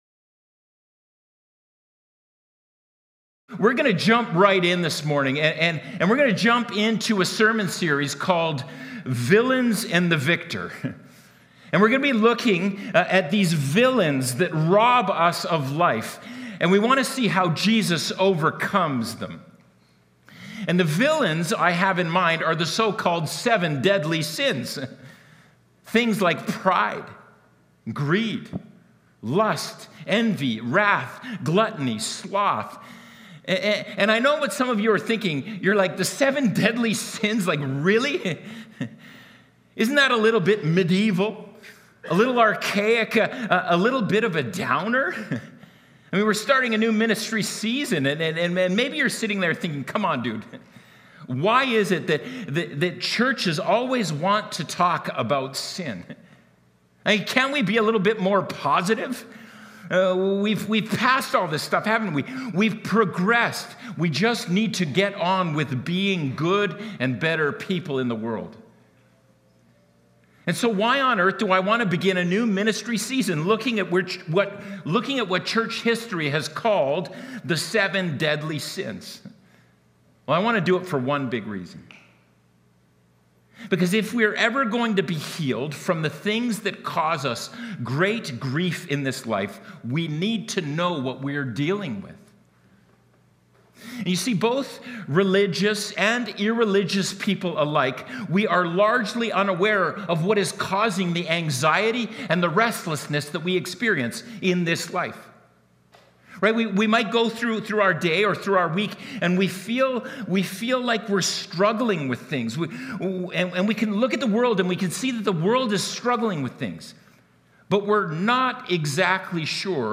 Sunday Sermon Sep 7, 2025.mp3